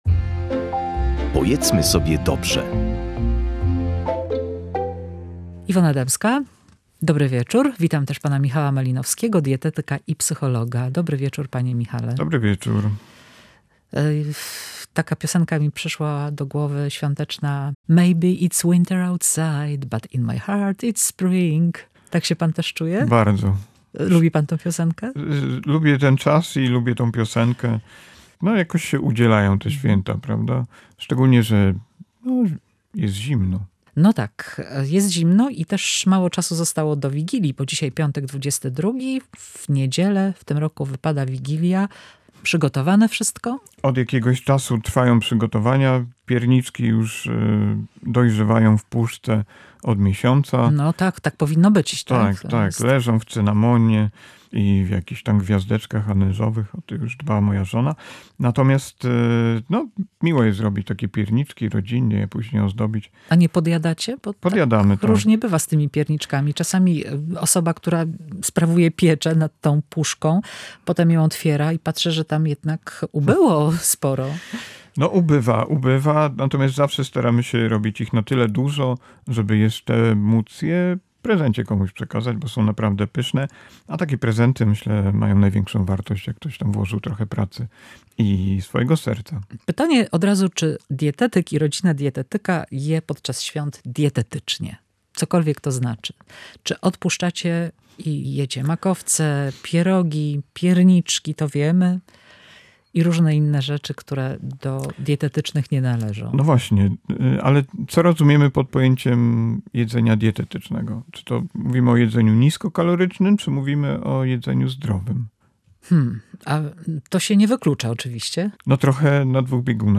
dietetyk i psycholog.